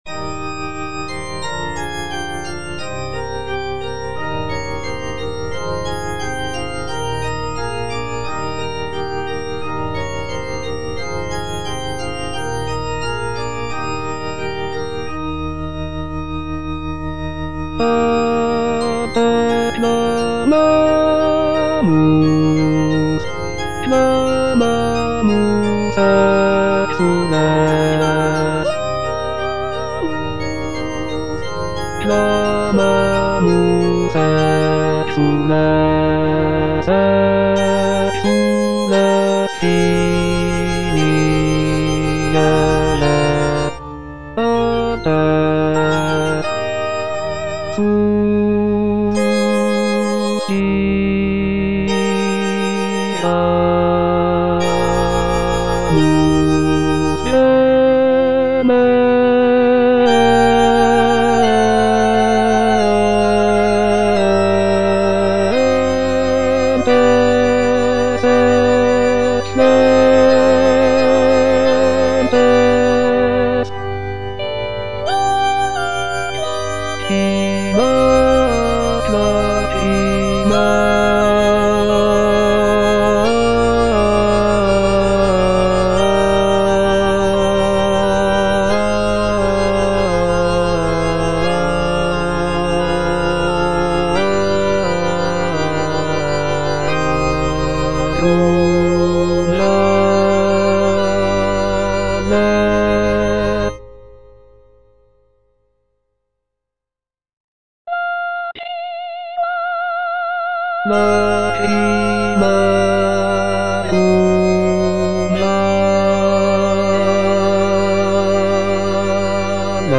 G.B. PERGOLESI - SALVE REGINA IN C MINOR Ad te clamamus - Bass (Emphasised voice and other voices) Ads stop: auto-stop